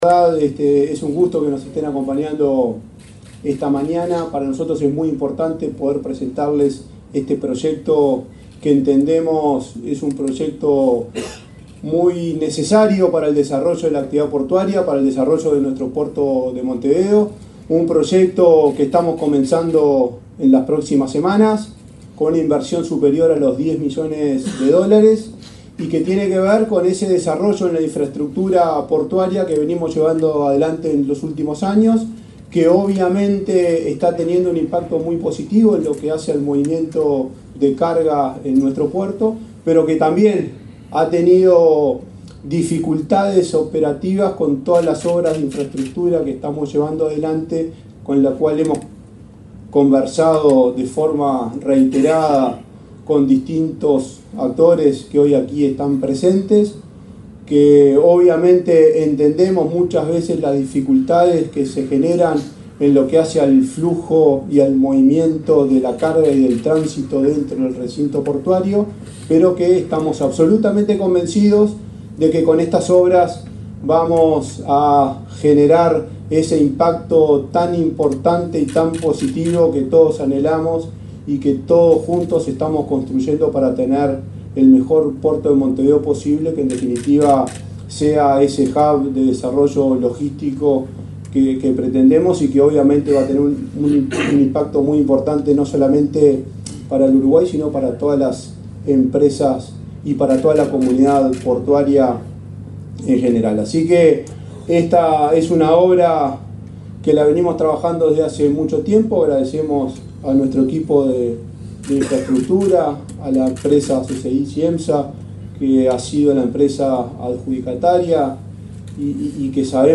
Palabras del presidente de la ANP, Juan Curbelo
Palabras del presidente de la ANP, Juan Curbelo 27/06/2024 Compartir Facebook X Copiar enlace WhatsApp LinkedIn La Administración Nacional de Puertos (ANP) presentó un proyecto de automatización de accesos terrestres al puerto de Montevideo. El presidente del organismo, Juan Curbelo, encabezó el evento.